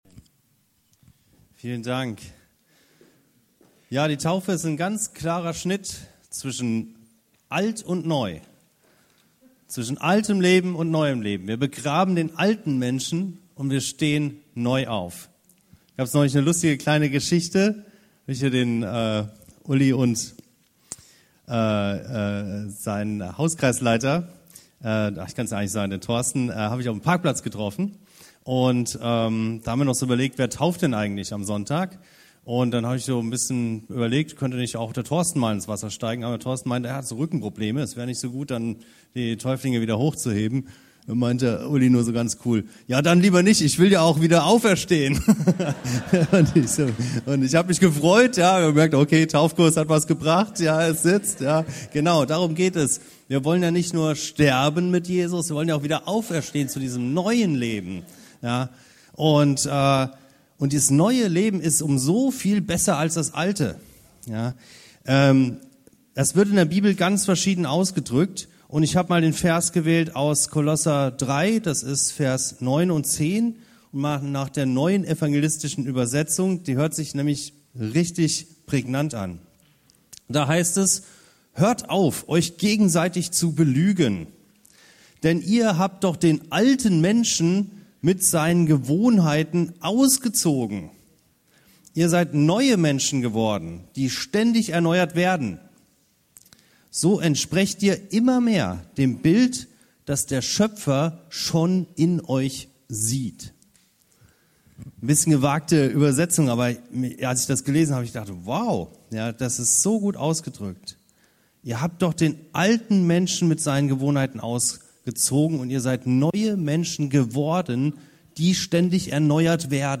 NEU sein heißt NEU werden, NEU werden heißt NEU sein. - Kol 3, 9-10 ~ Anskar-Kirche Hamburg- Predigten Podcast